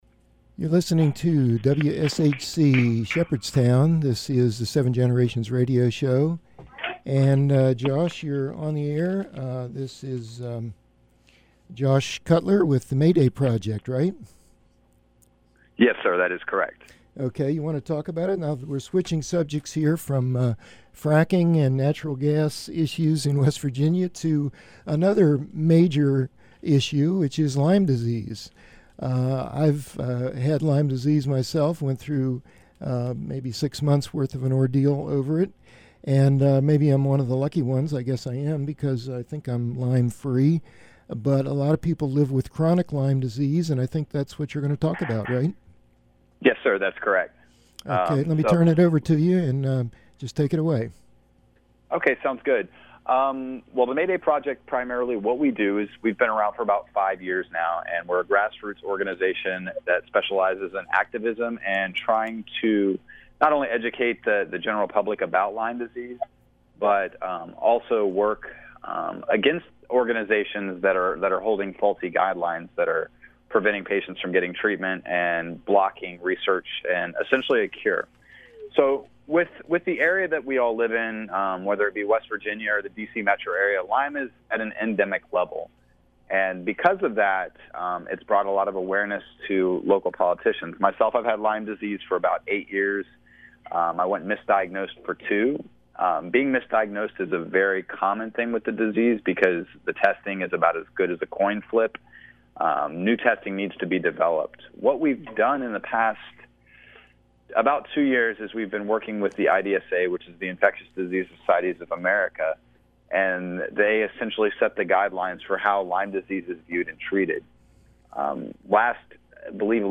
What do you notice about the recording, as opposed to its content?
Audio program was broadcast on WSHC Radio, Shepherd University, Shepherdstown, WV on May 30, 2015 https